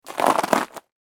Crunchy Snow Footstep Sound Effect
Description: Crunchy snow footstep sound effect. A clean, high-quality foley sound effect of a single footstep on crispy, frozen snow.
Crunchy-snow-footstep-sound-effect.mp3